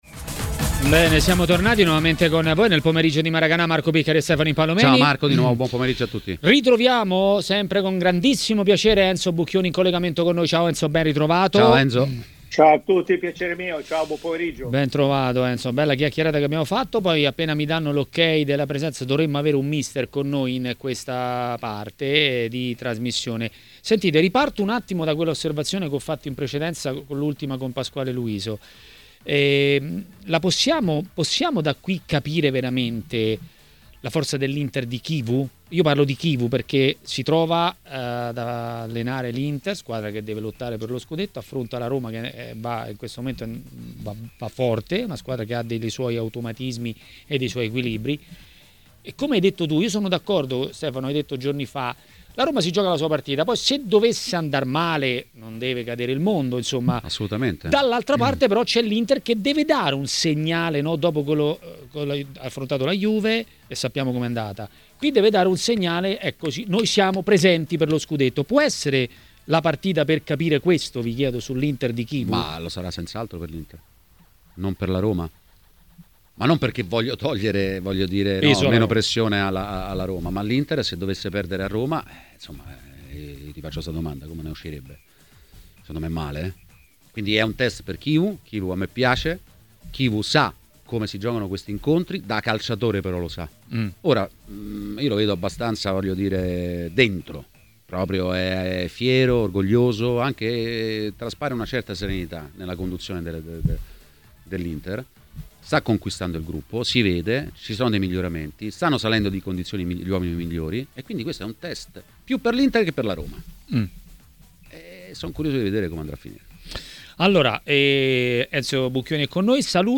ospite di Maracanà, trasmissione di TMW Radio.